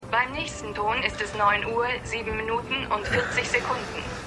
Ed_1x01_Telefonstimme.mp3